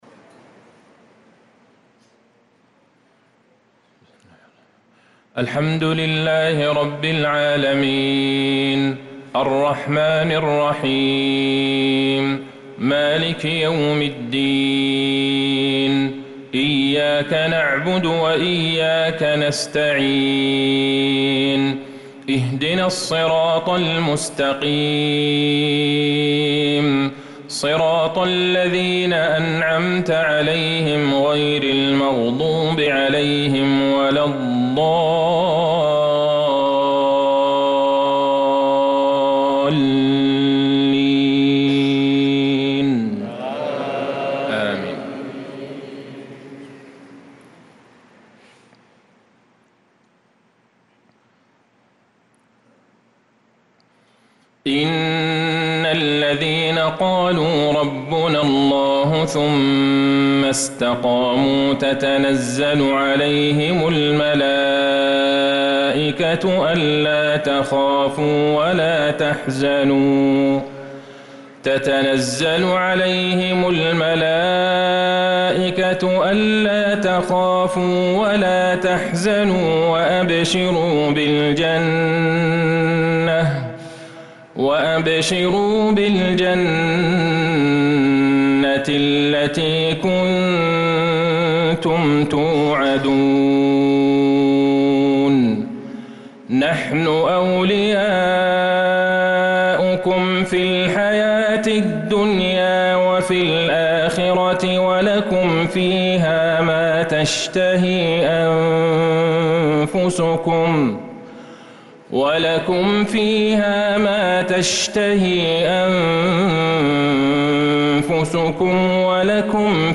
صلاة المغرب للقارئ عبدالله البعيجان 24 ذو الحجة 1445 هـ
تِلَاوَات الْحَرَمَيْن .